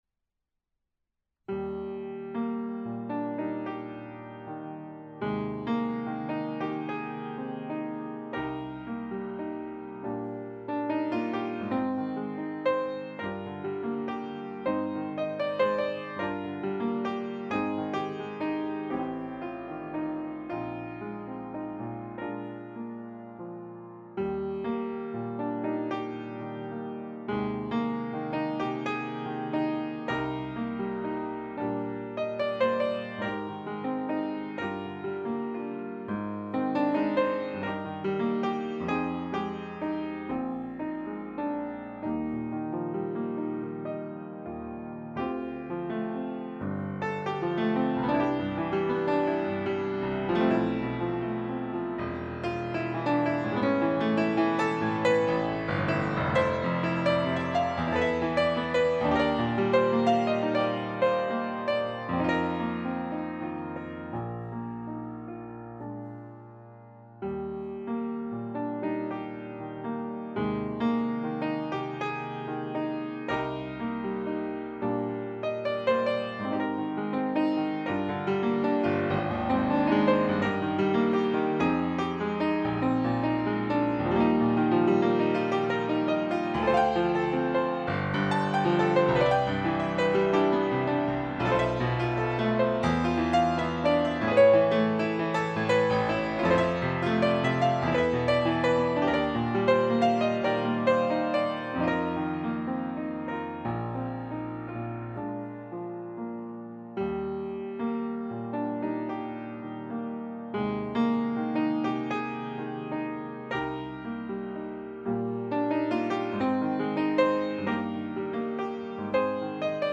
piano - romantique - melancolique - nostalgique - melodique